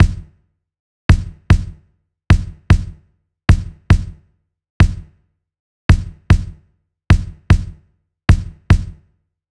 Unison Funk - 2 - 100bpm - Kick.wav